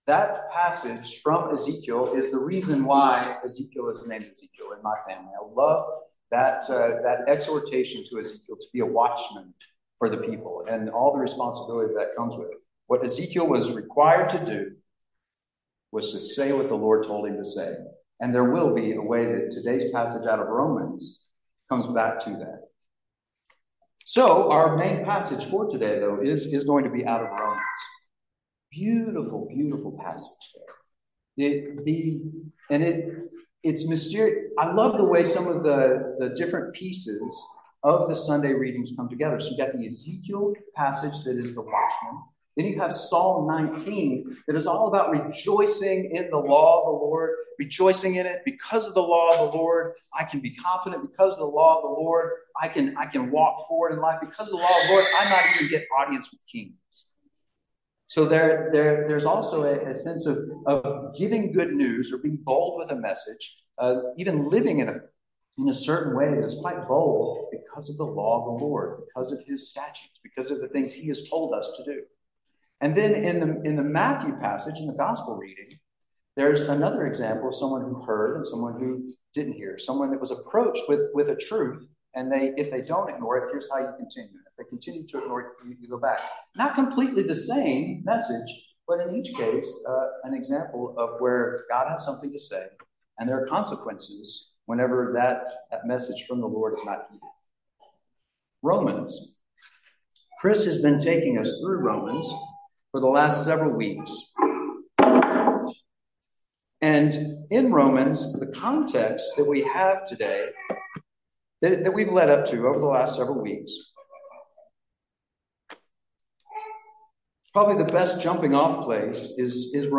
Sermons | Christ the King